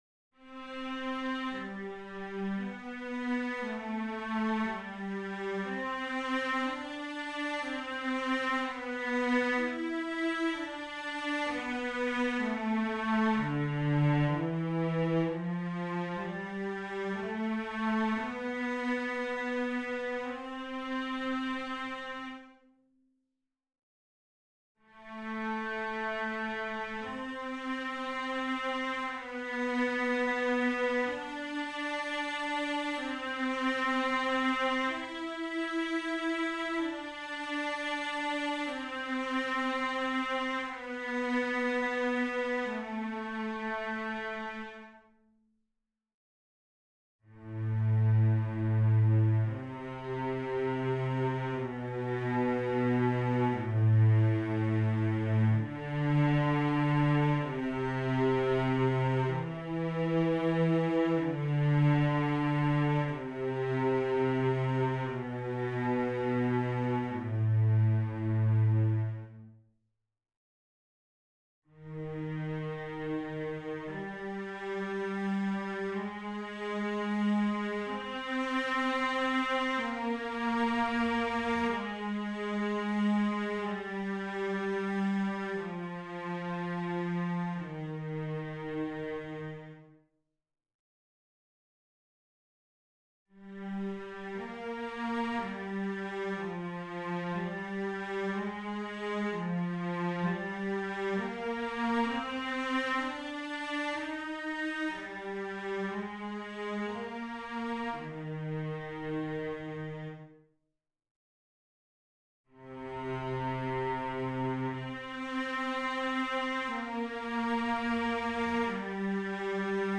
Analyse counterpoint exercises
good-cp2-13-Cellos_0.mp3